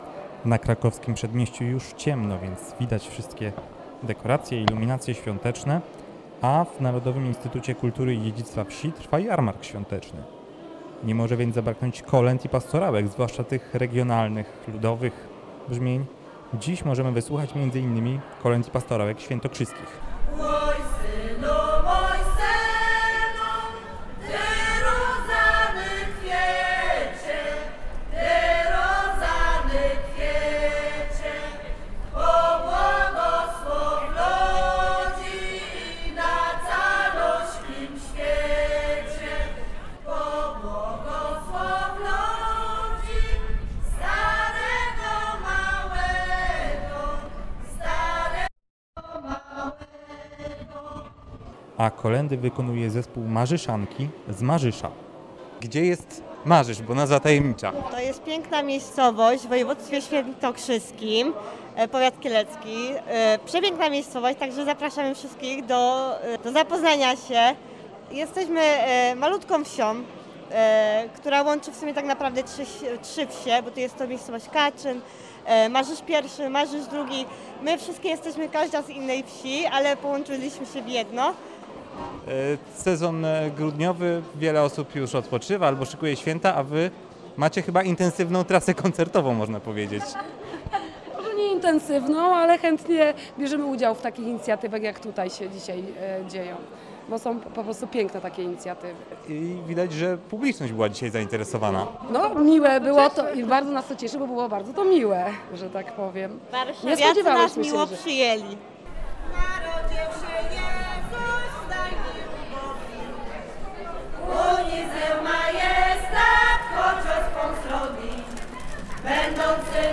Muzyka, ozdoby świąteczne oraz regionalne potrawy – to główne atrakcje Jarmarku Świątecznego, który odbył się w Narodowym Instytucie Kultury i Dziedzictwa Wsi na Krakowskim Przedmieściu w Warszawie.